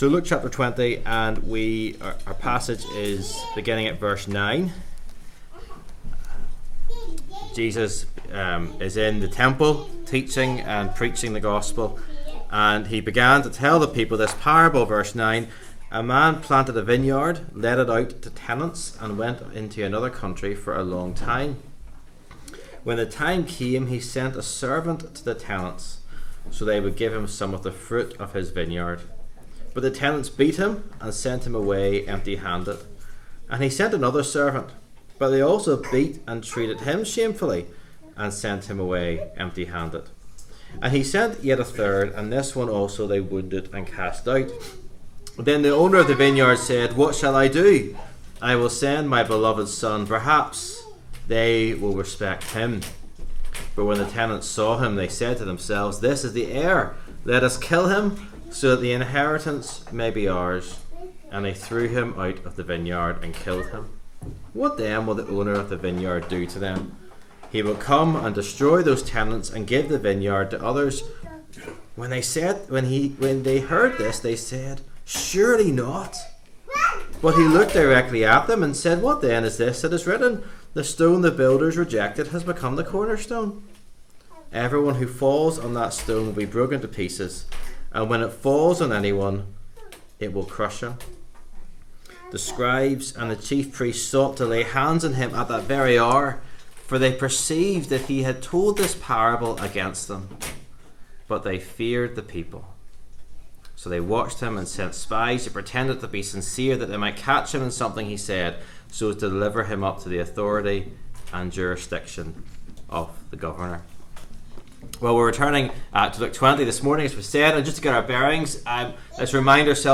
Luke 20:9-20 Service Type: 11am Topics